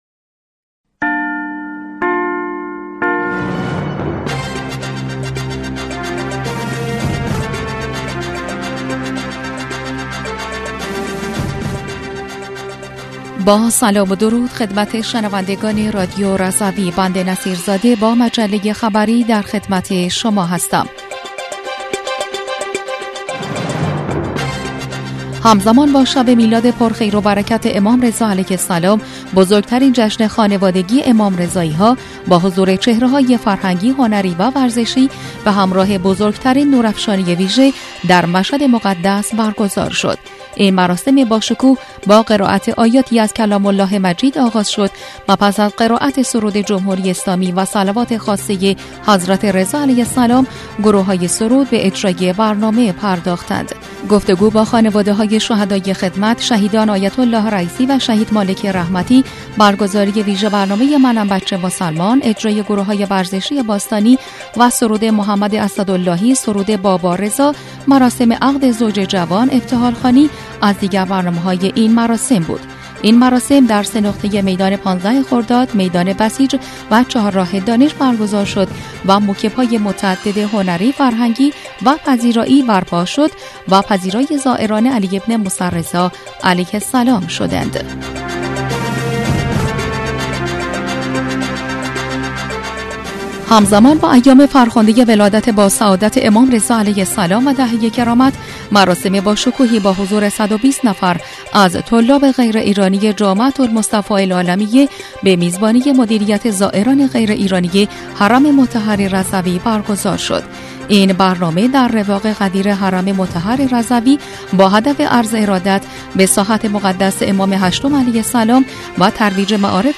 بسته خبری 19اردیبهشت رادیو رضوی؛